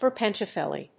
音節per･pen･dic･u･lar･ly発音記号・読み方pə̀ːrpəndɪ́kjələrli